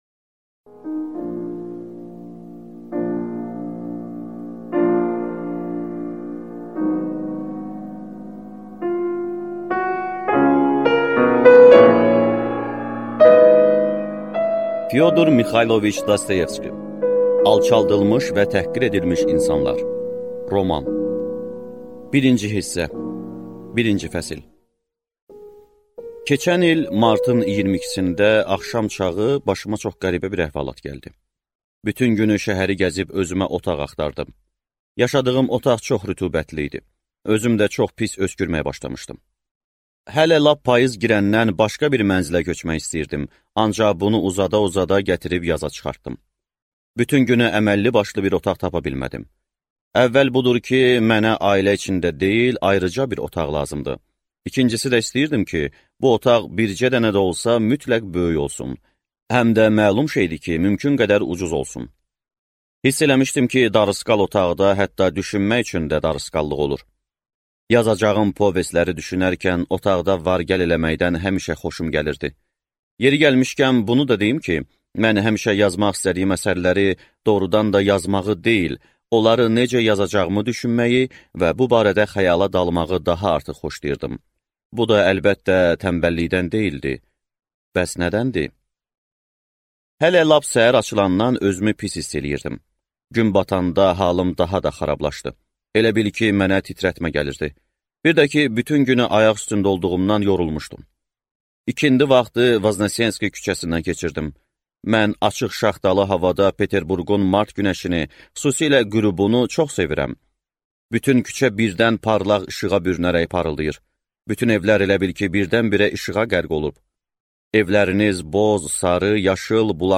Аудиокнига Alçaldılmış və təhqir edilmiş insanlar | Библиотека аудиокниг